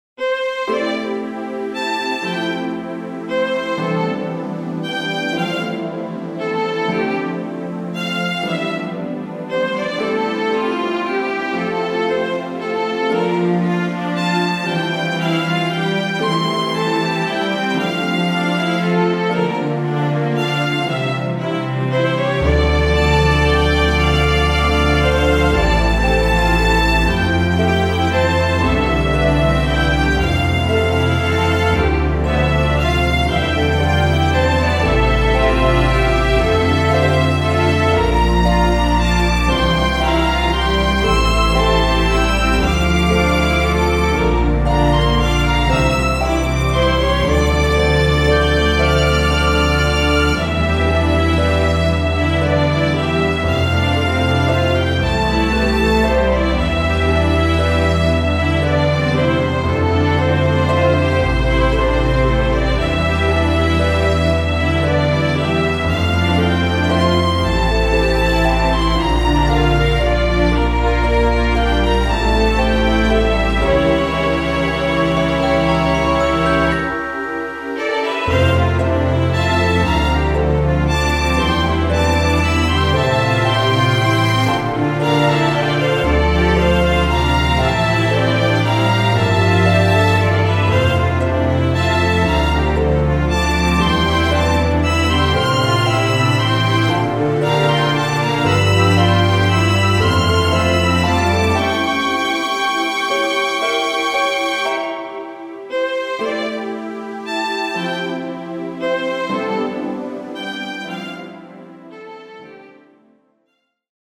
フリーBGM イベントシーン 幻想的・神秘的
フェードアウト版のmp3を、こちらのページにて無料で配布しています。